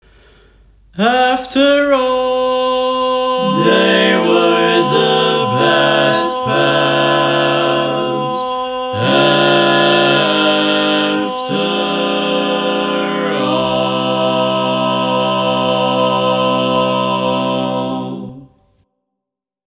Key written in: C Major
How many parts: 4
Type: Barbershop
All Parts mix: